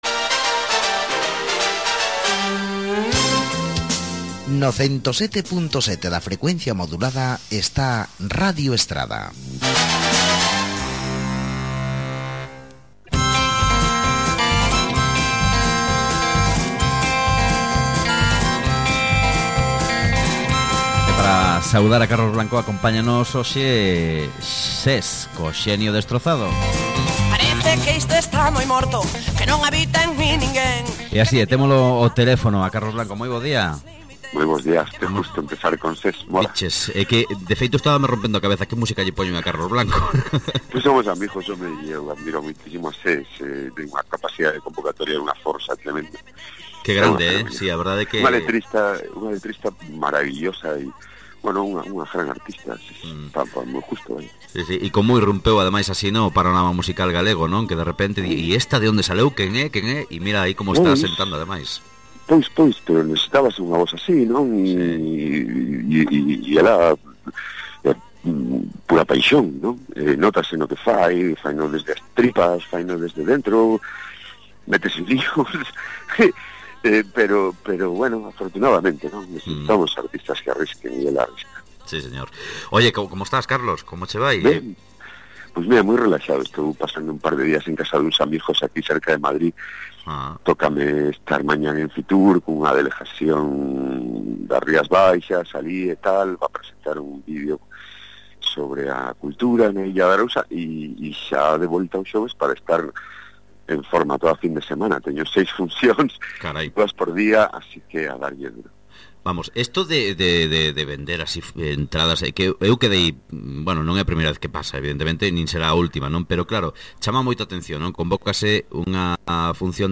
Charlamos disto con el nunha conversa ben agradable.